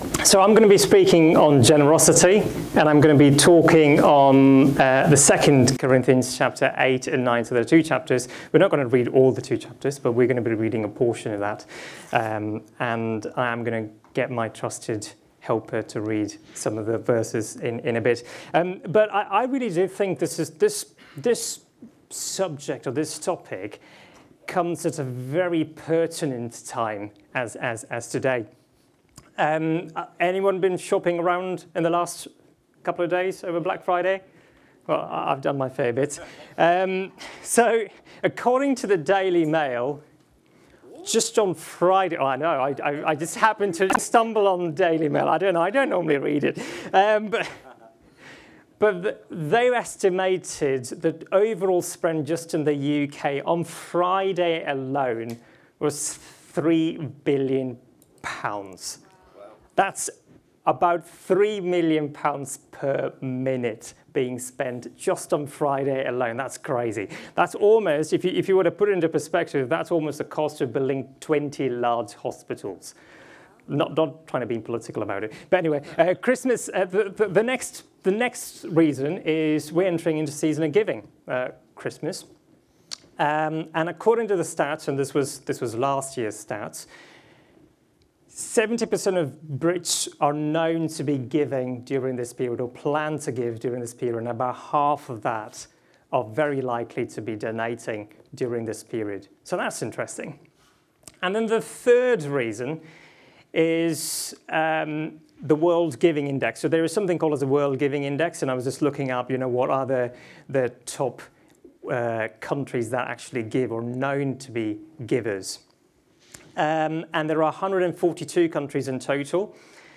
Download Generosity | Sermons at Trinity Church